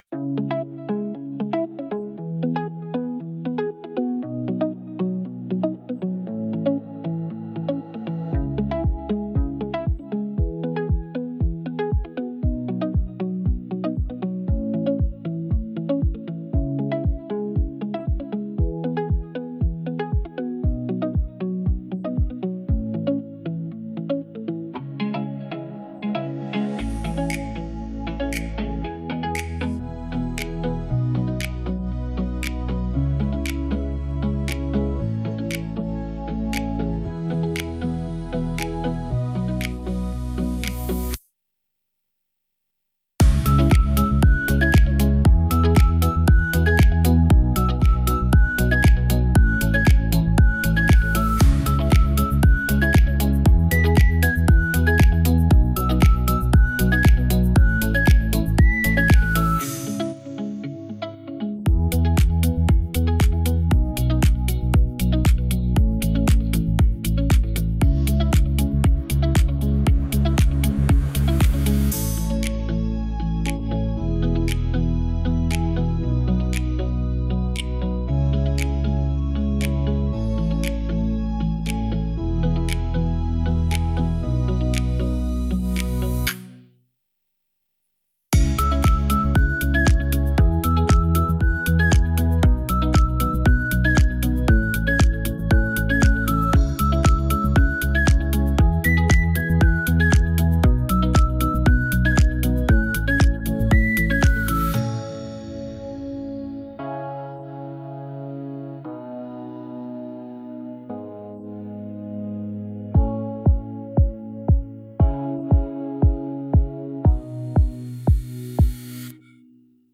Tropical House · 117 BPM · Eng